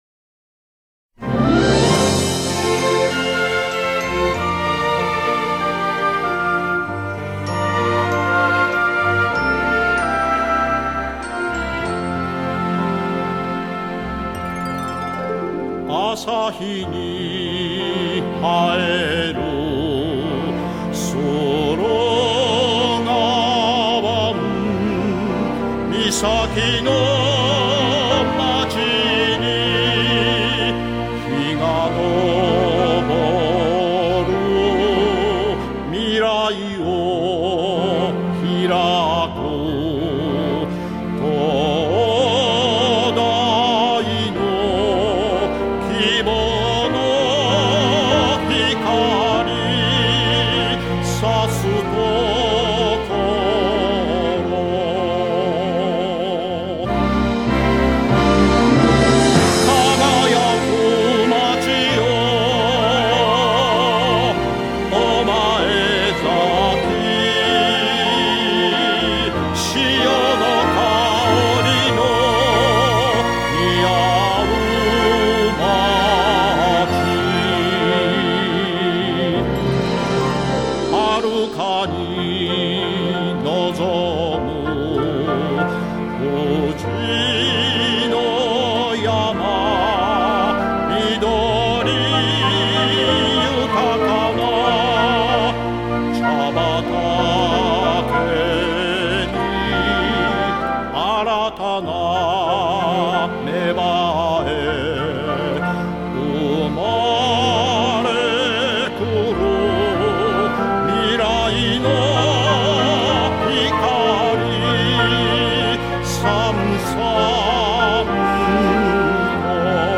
オリジナルバージョン